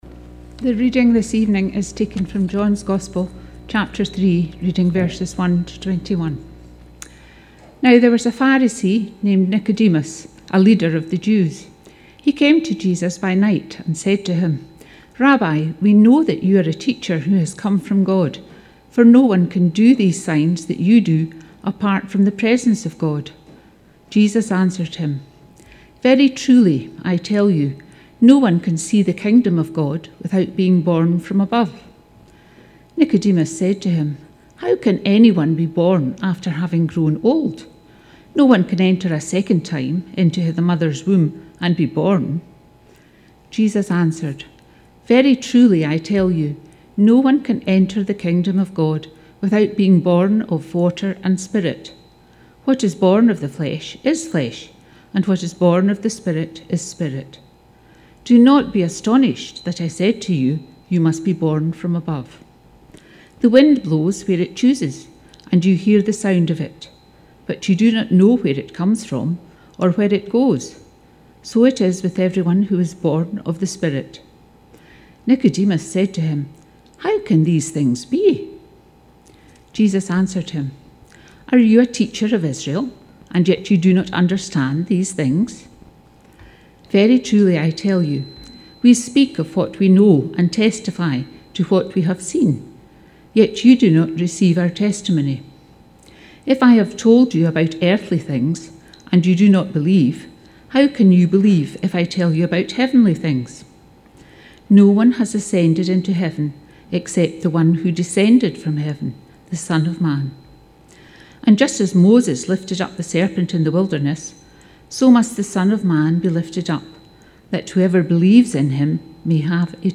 Penicuik Trinity Community Church was led by Presbytery on Thursday 7 April.
Bible passage